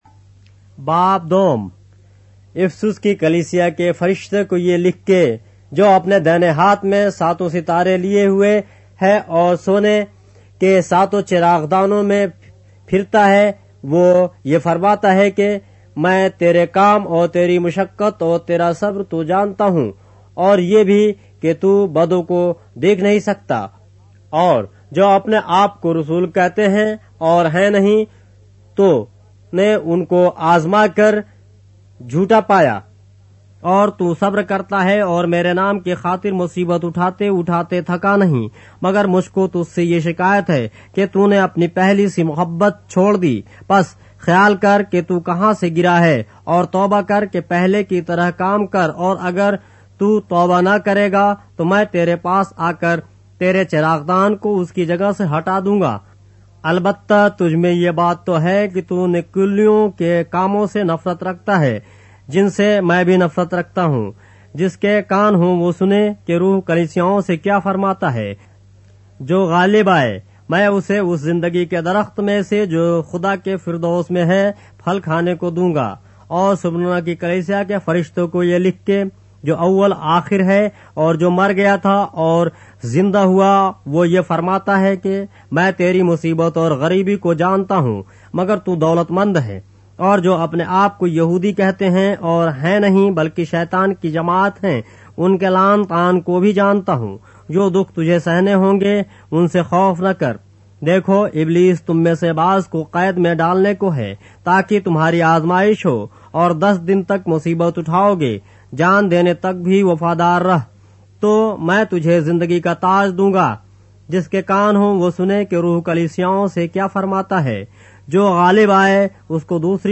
اردو بائبل کے باب - آڈیو روایت کے ساتھ - Revelation, chapter 2 of the Holy Bible in Urdu